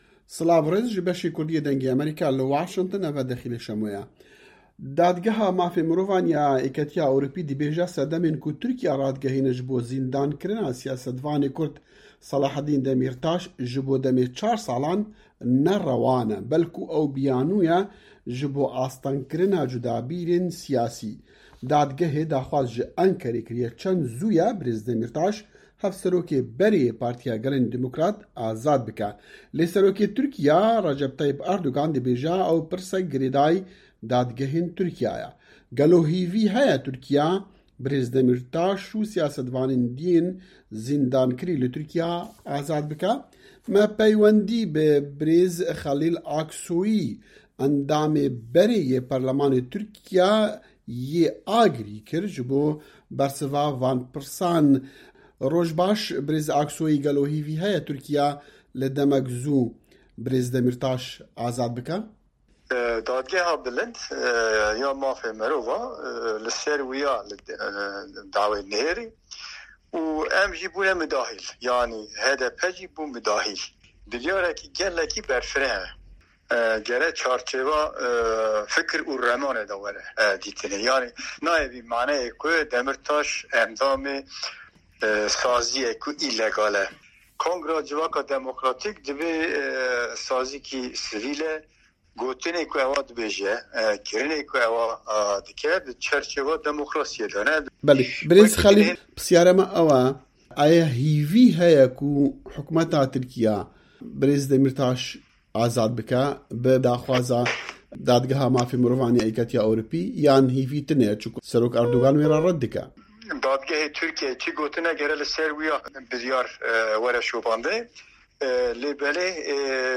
Herêmên Kurdan - Hevpeyvîn
Di hevpeyvînekê de digel Dengê Amerîka endamê parlamentoya Tirkîyê yê berê Xelîl Aksoyî yê Agrî got ku pêdivî ye ku dadgehên Tirkîyê jî rêzê li biryarên dadgeha ECHR bigrin.